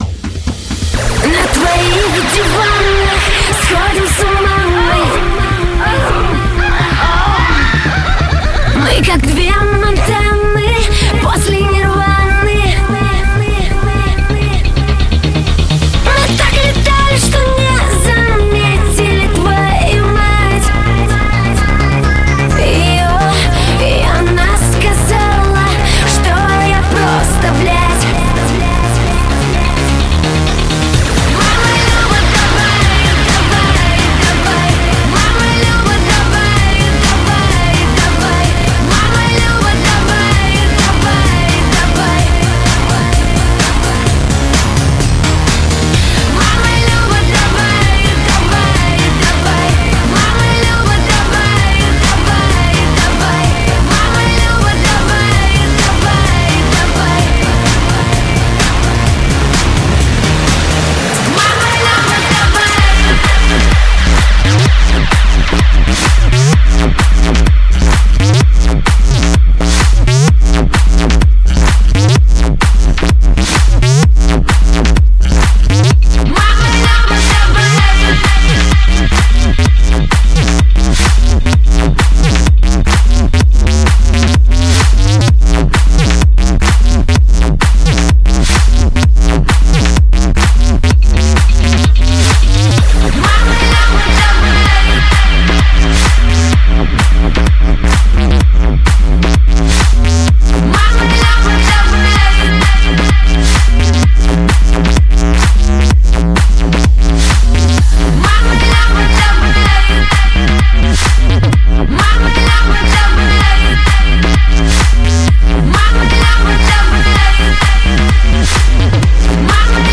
Категория: Drum'n'Bass